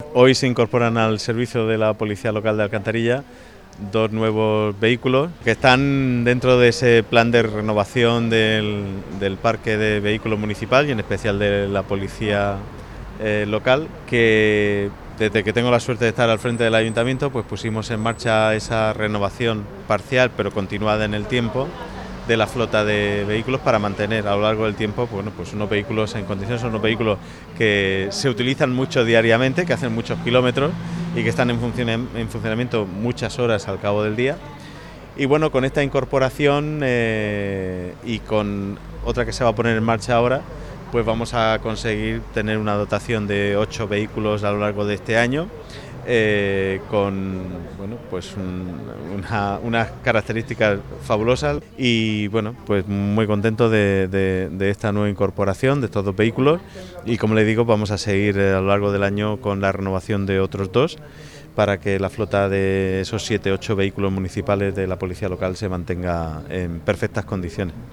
Declaraciones del alcalde de Alcantarilla, Joaquín Buendía.